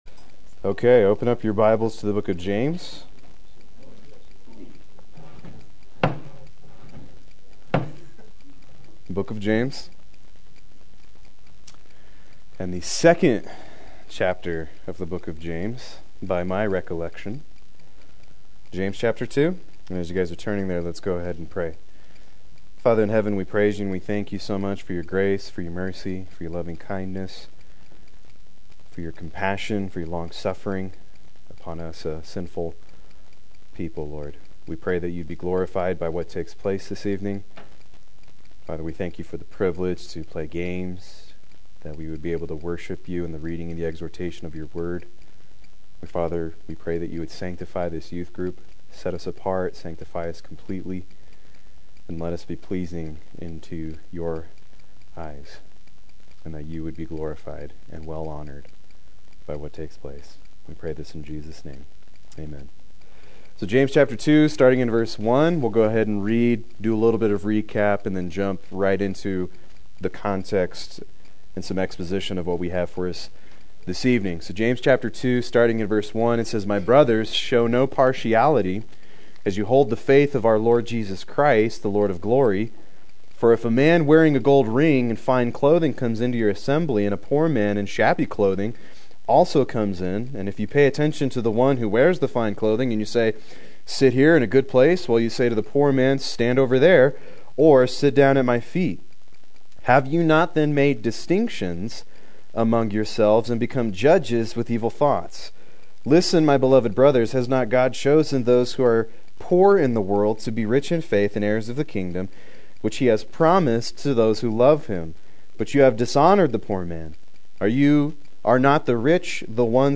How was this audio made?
Proclaim Youth Ministry - 09/25/15